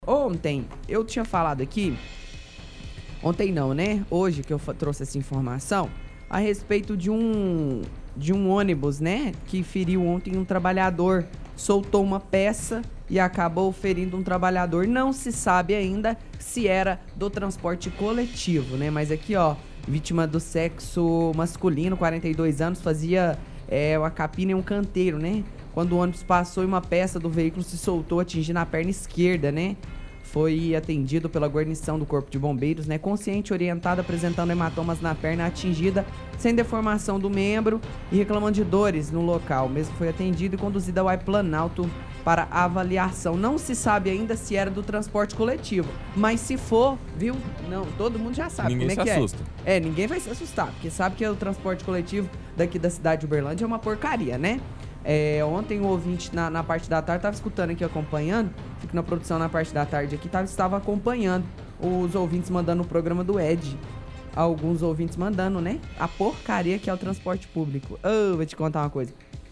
– Retransmissão de áudio de ontem de ouvinte criticando o transporte público no bairro São Jorge e pedindo volta dos veículos beija-flor.